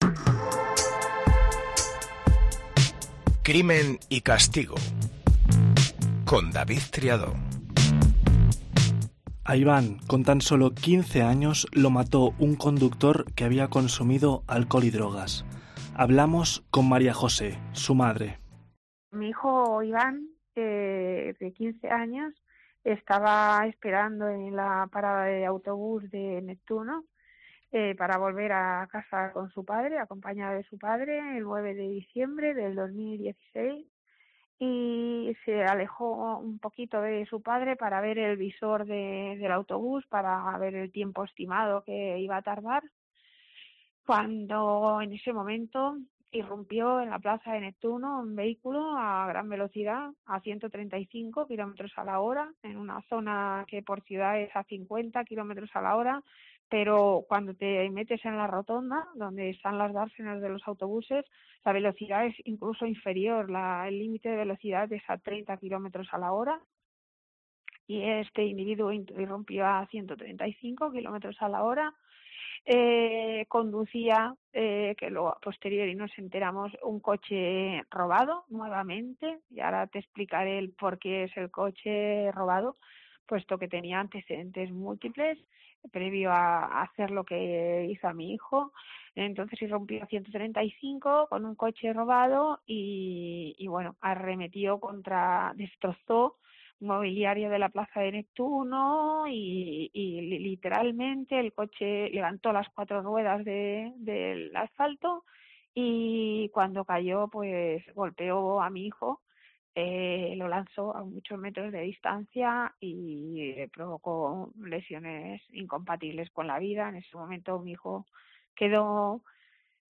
Con la voz rota, explica los desgarradores pormenores de una tragedia que, de no haber sido por la cantidad de alcohol y drogas que había consumido el responsable, nunca se habría producido.